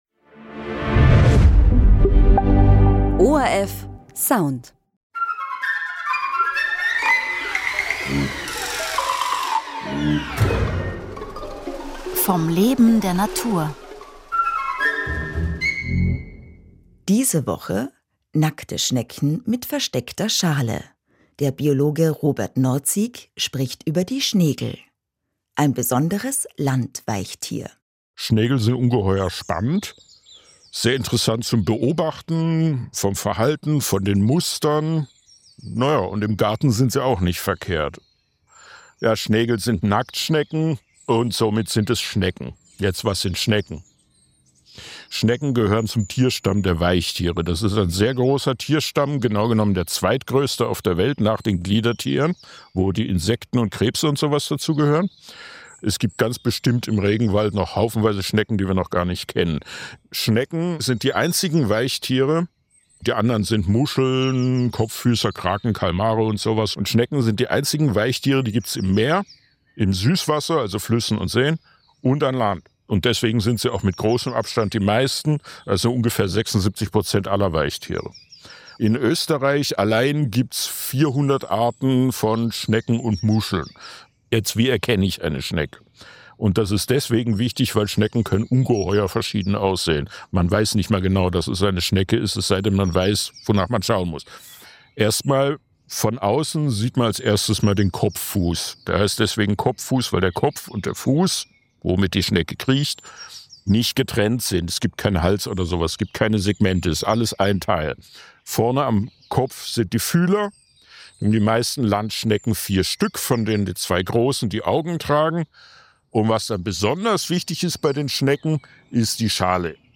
Radiointerview im ORF1 Radio